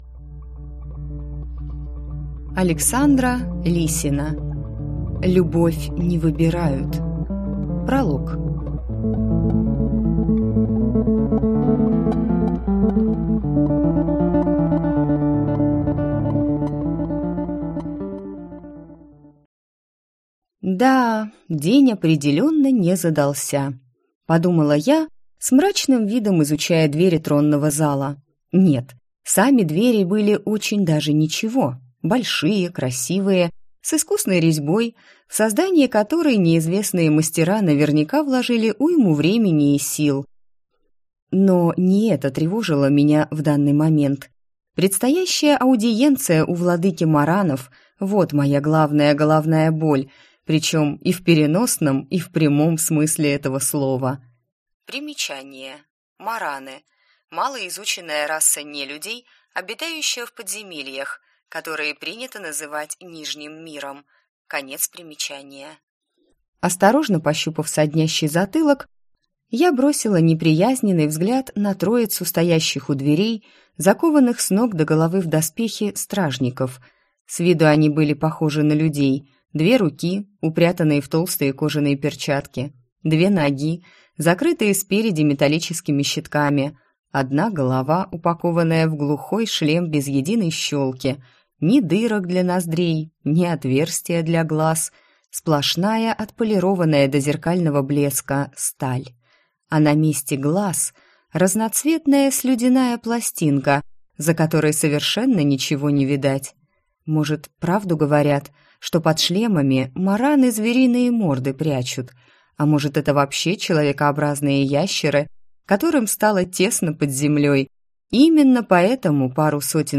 Аудиокнига Любовь не выбирают | Библиотека аудиокниг